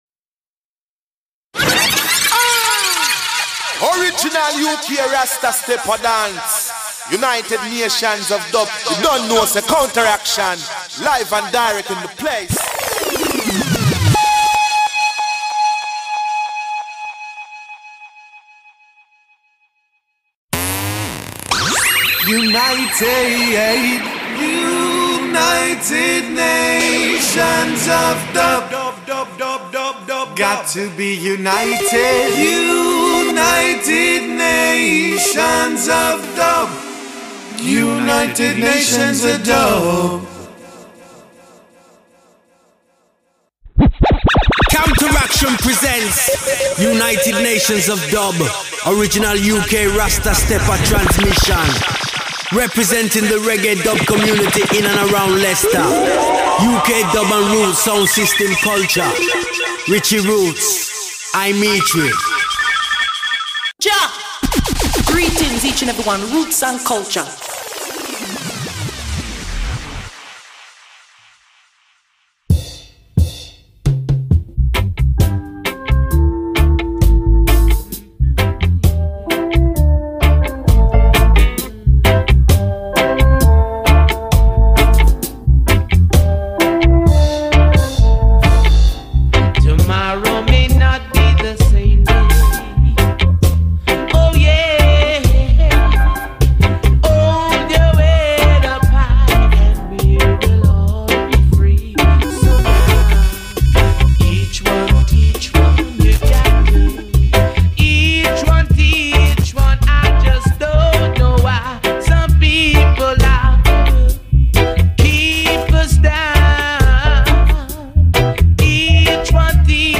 Disco Mix galore!!